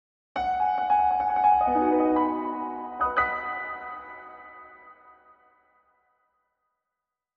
applause.wav